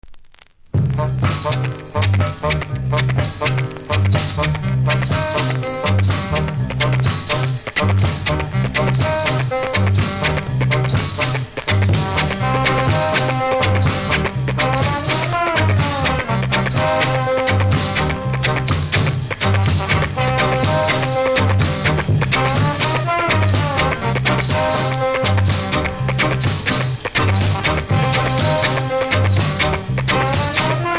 キズ多めですが音は良好なので試聴で確認下さい。